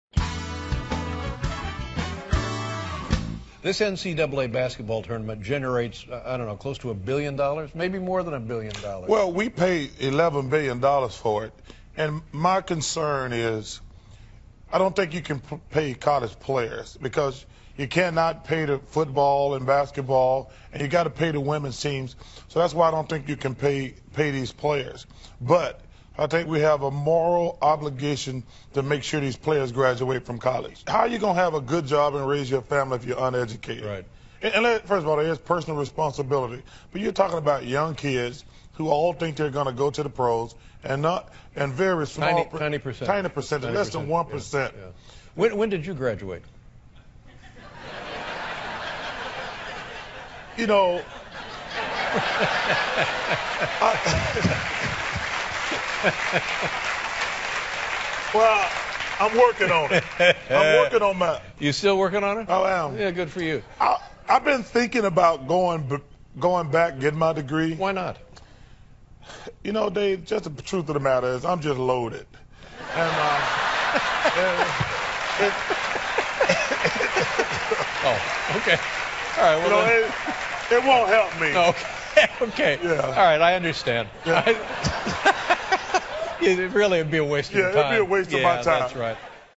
访谈录 2011-03-17&03-19 查尔斯·巴克利专访 听力文件下载—在线英语听力室